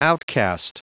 Prononciation du mot outcast en anglais (fichier audio)
Prononciation du mot : outcast